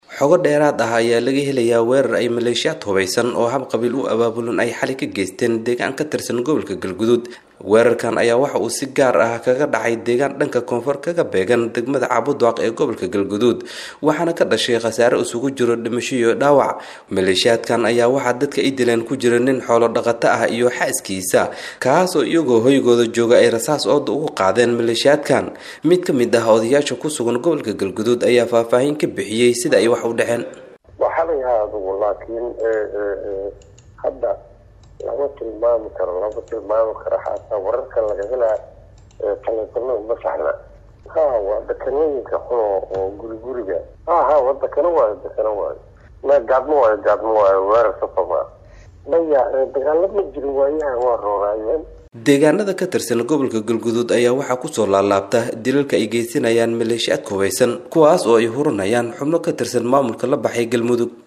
Mid kamid ah Odayaasha ku sugan gobalka ayaa faahfaahin ka bixiyay sida ay wax u dhaceen.